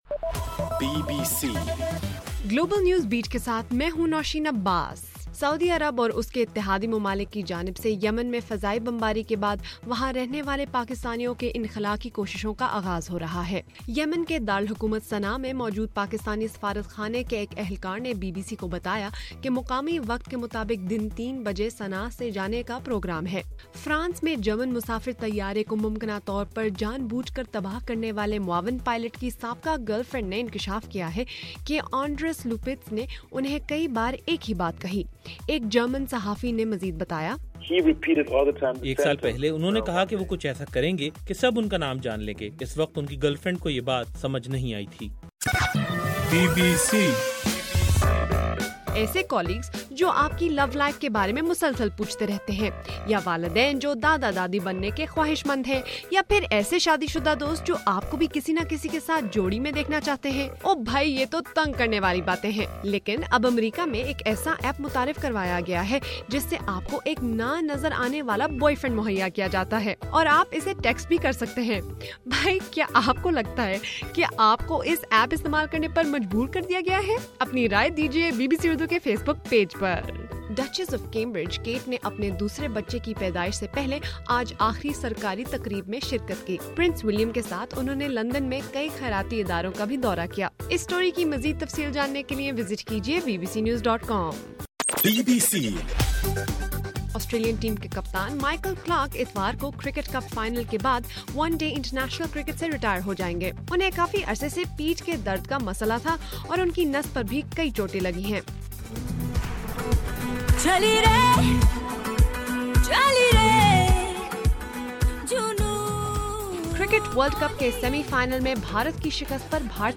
مارچ 28: رات 8 بجے کا گلوبل نیوز بیٹ بُلیٹن